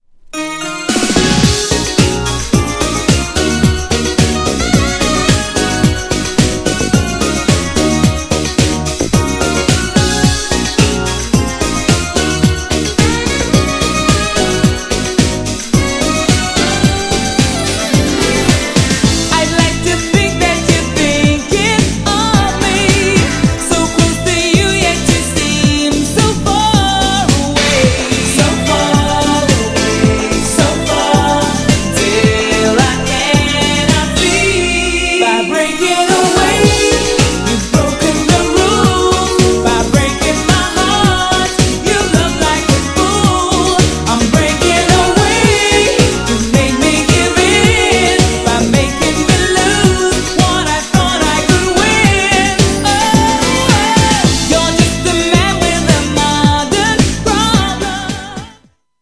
GENRE R&B
BPM 91〜95BPM
# キャッチーなR&B # メロディアスR&B # 女性VOCAL_R&B